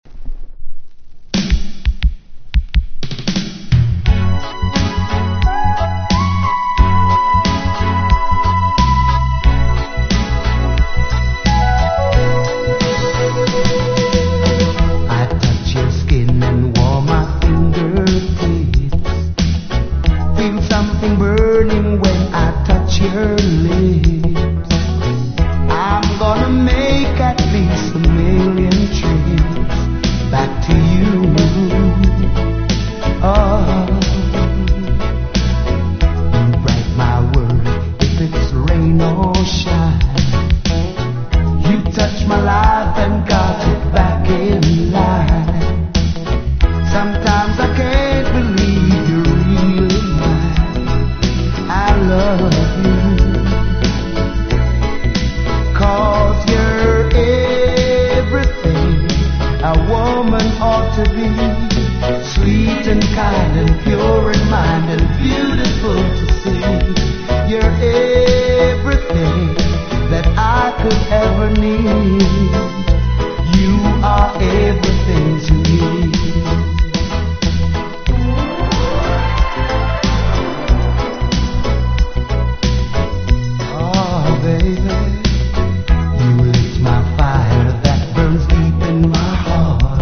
1. REGGAE >